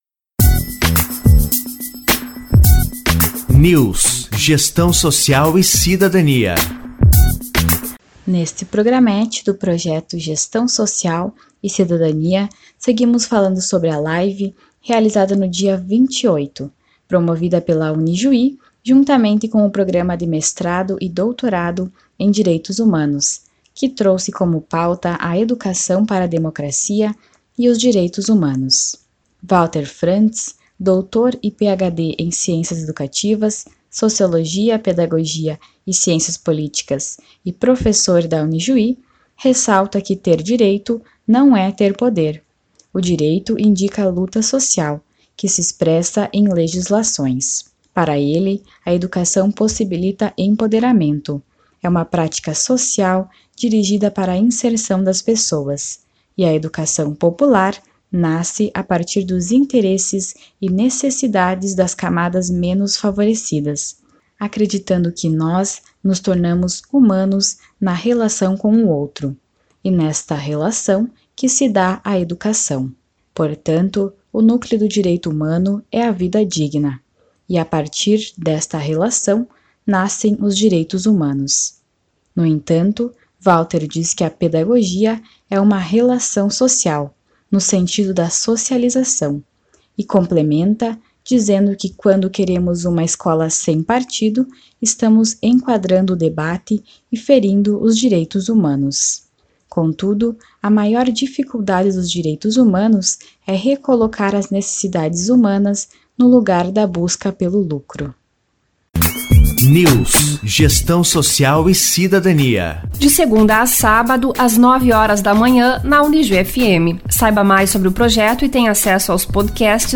Live do dia 28/05